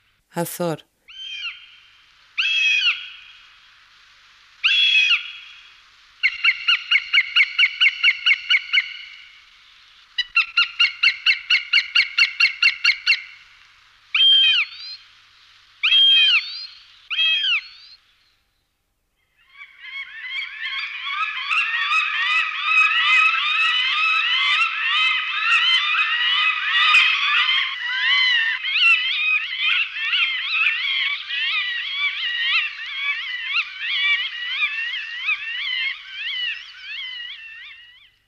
Astore (Accipiter gentilis)